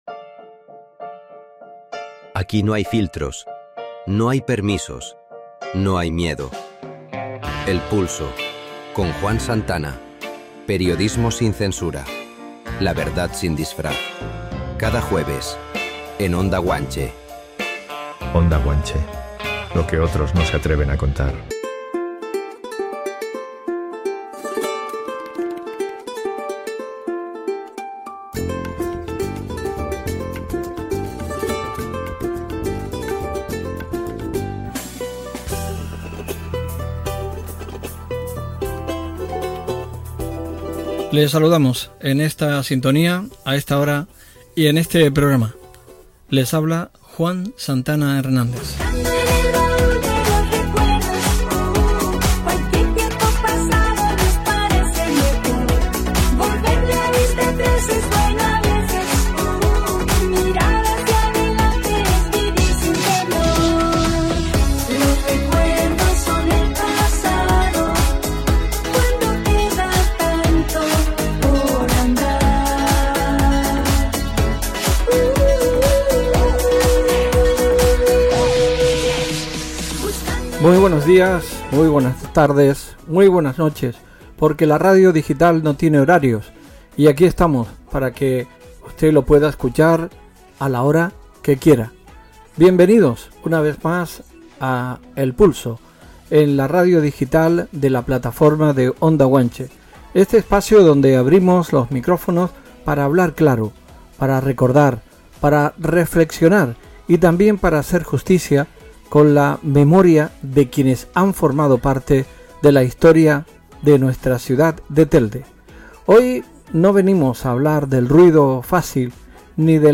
La exalcaldesa revive en Onda Guanche su paso por el Ayuntamiento, el Congreso de los Diputados y el Gobierno de Canarias, en una entrevista marcada por la memoria, la emoción y el compromiso con su ciudad.
En El Pulso, el programa que se emite en la Radio Digital de la Plataforma de Onda Guanche, hubo esta vez una entrevista con memoria, con sentimiento y con el respeto que merecen las trayectorias políticas que dejan huella en una ciudad.